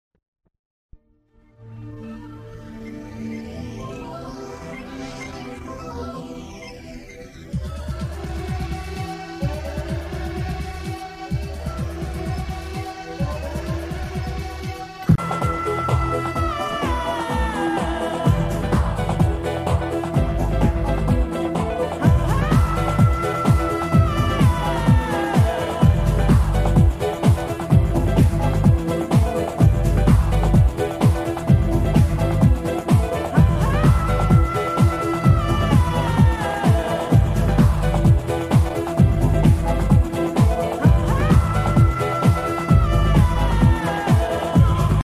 Wailing and furious beats. Maybe it's an overly sampled DJ mix of many things.